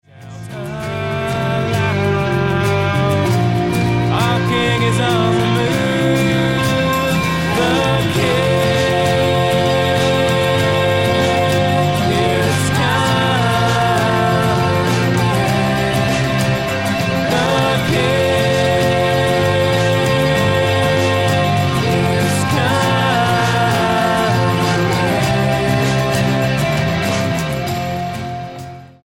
STYLE: Pop
with plenty of guitars and a catchy chorus